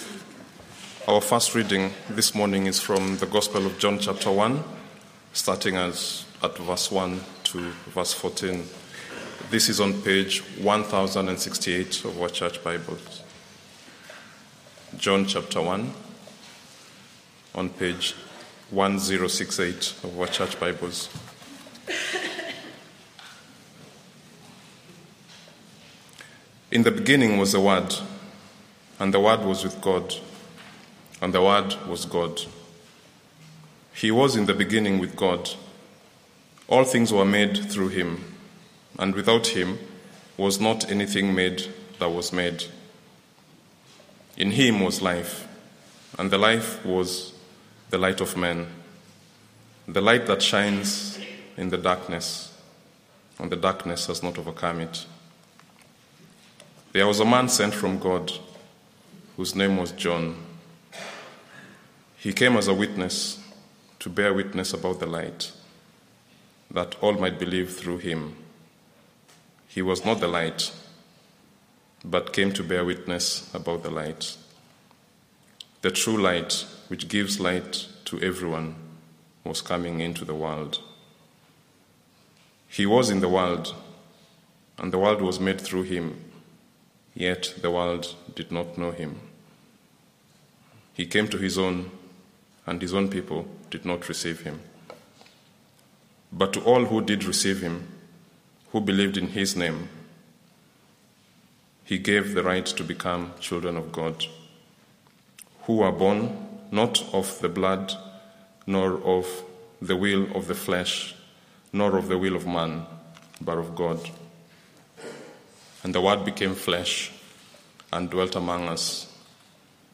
Why did Jesus come? Sermon - Audio Only Search media library...